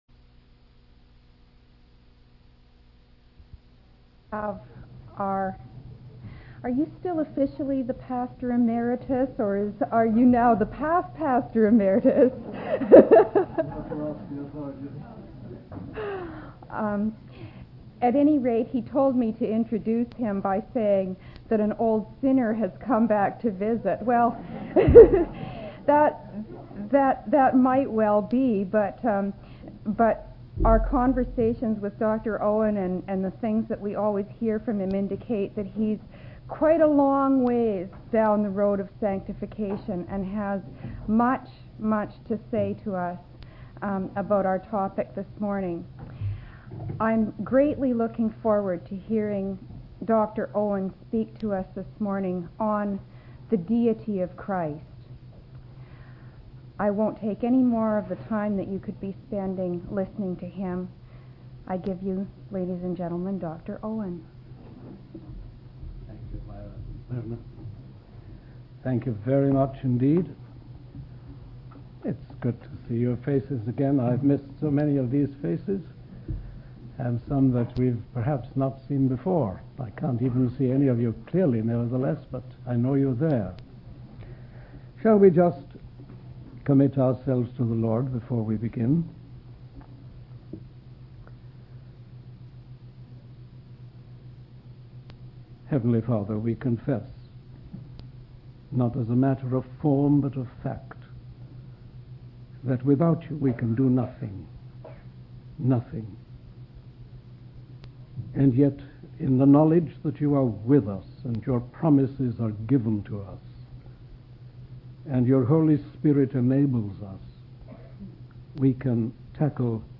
In this sermon, the preacher discusses the deity of Jesus Christ. He emphasizes that Jesus claimed to be divine in an absolute sense, a belief that sets Christianity apart from other religions. The preacher explains that the Christian view of God includes a personal God who is the creator, ruler, infinite, eternal, and unchangeable.